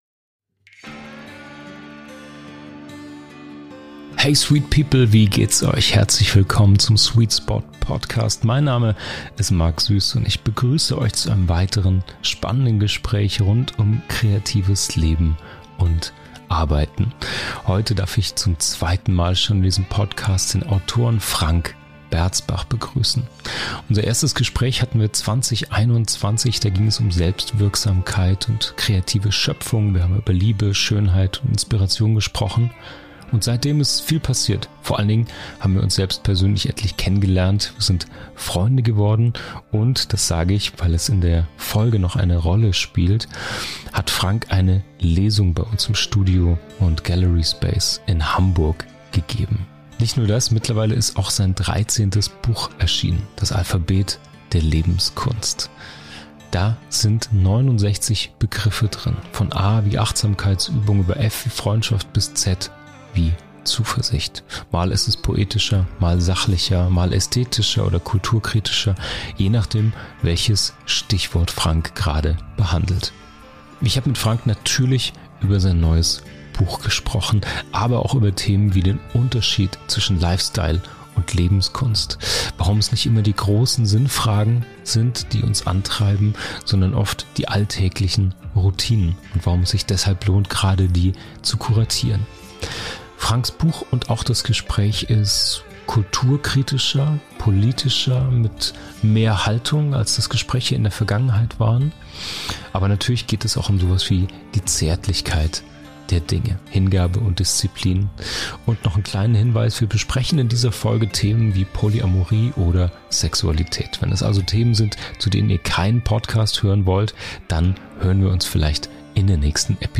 Listen to conversations with artists, entrepreneurs, and media personalities and how they found their sweet spot. Get inspiration, news, and tips for brand building, creative work, and storytelling.